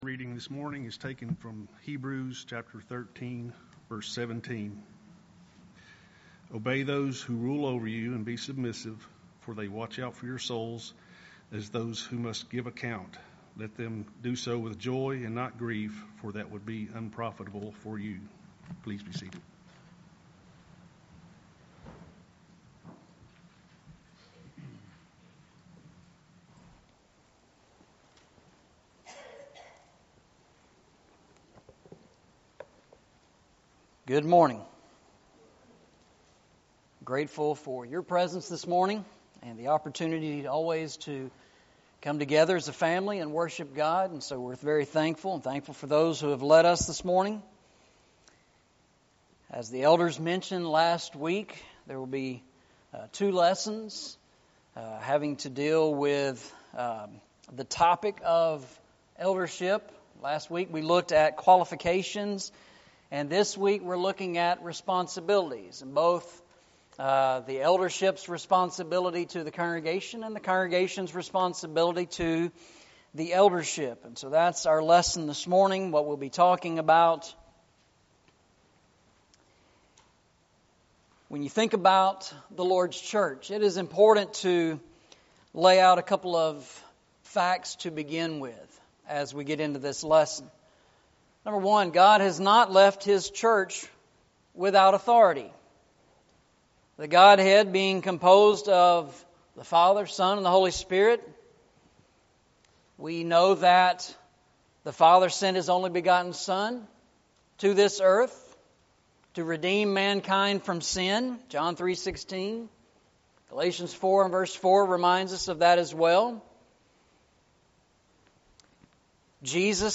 Eastside Sermons
Service Type: Sunday Morning